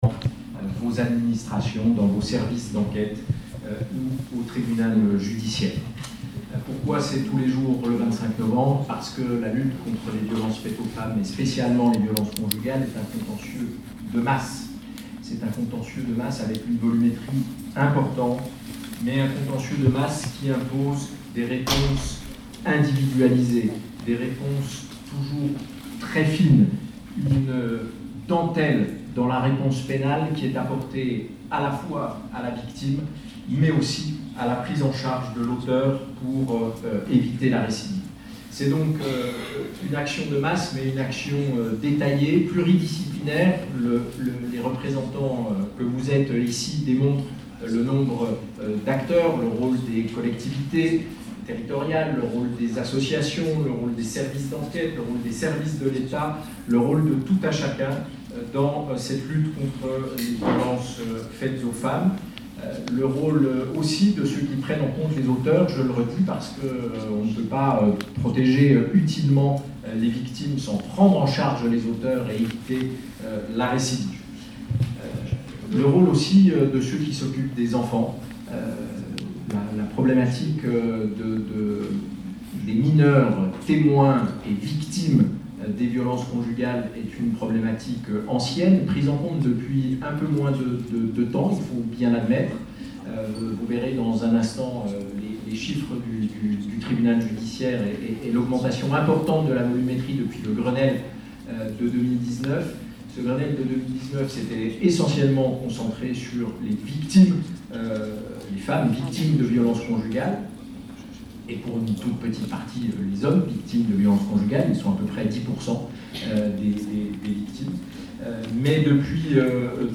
Shalom Bourgogne était présente le 25 novembre 2025 pour la signature dunouveau protocole départemental 2025-2030 de prévention et de lutte contreles violences conjugales.